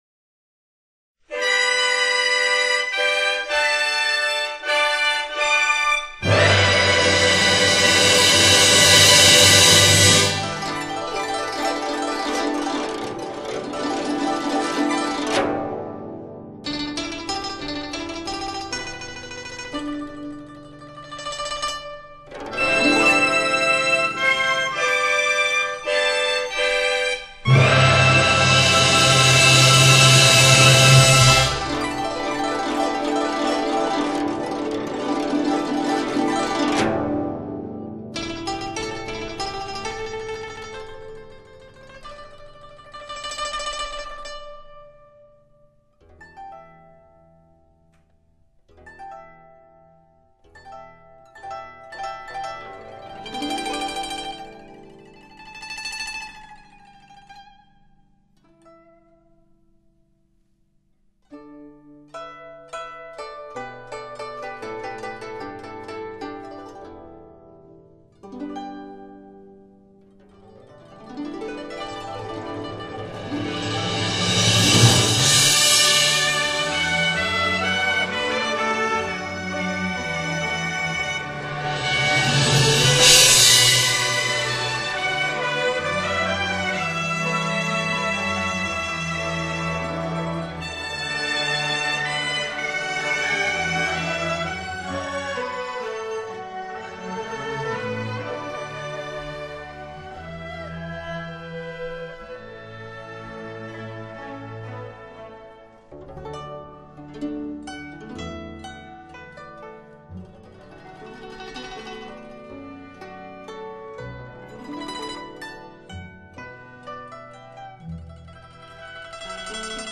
柳琴，筝，中阮协奏曲)(低品质的片断)